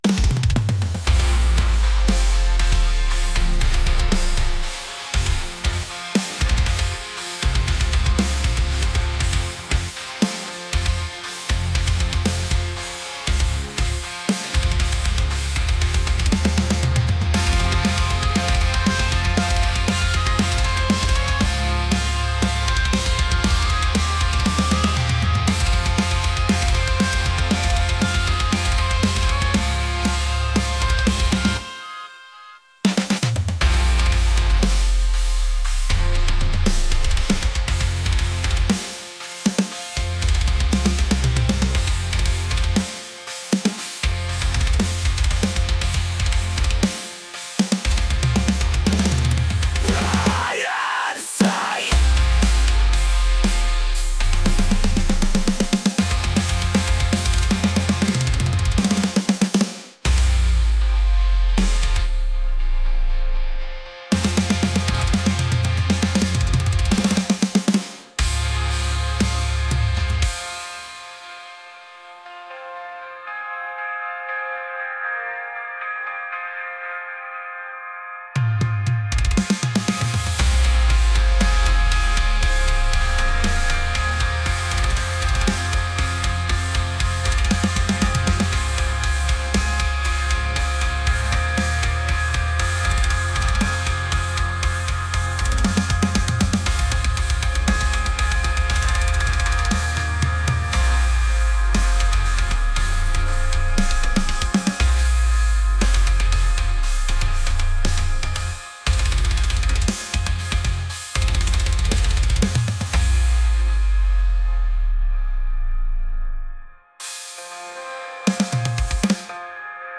aggressive | intense